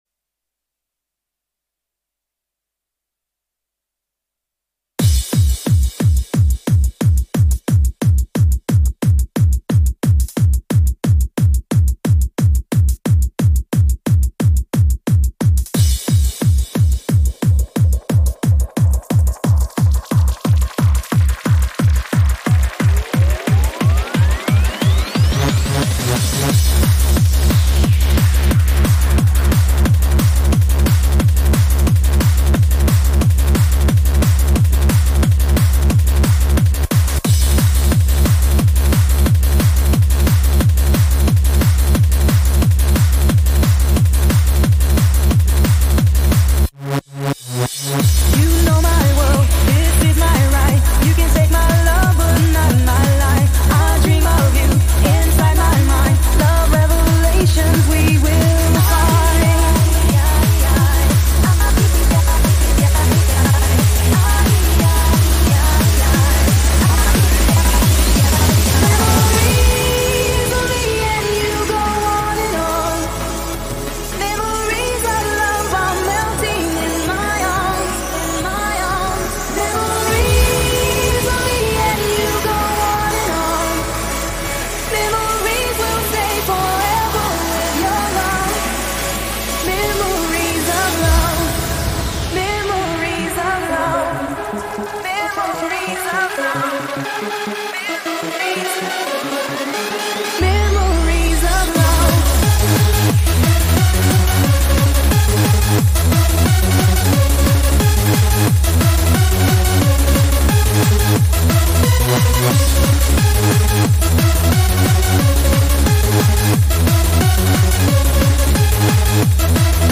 On CDJ's
Vocals Hardcore Happy Hardcore